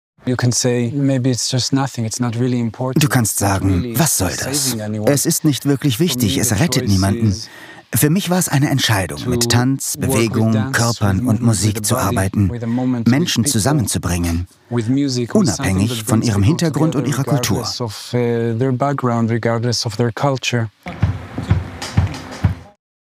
markant, dunkel, sonor, souverän
Mittel minus (25-45)